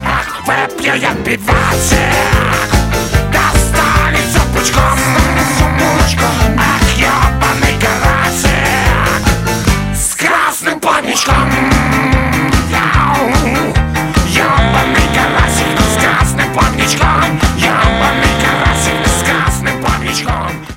• Качество: 192, Stereo
смешные